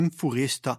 in furesta [ f ] : sourde